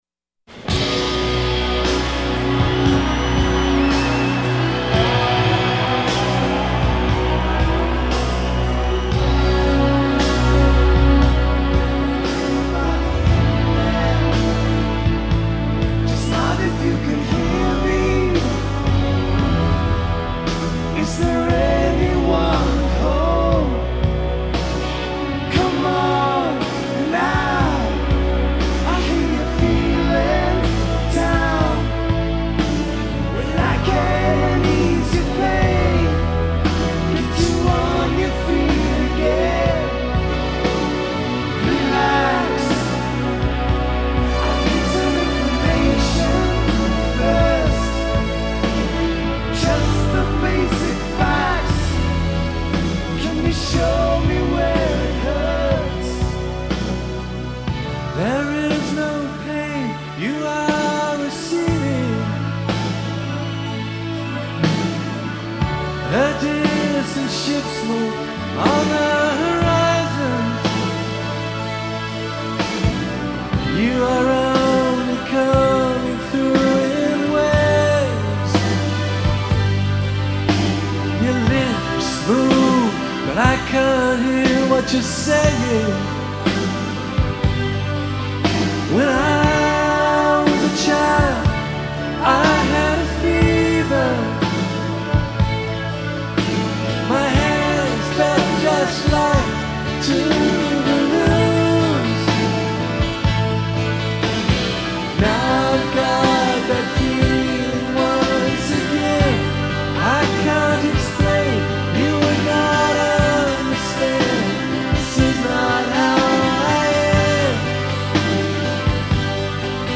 particularly fiery lead guitar solo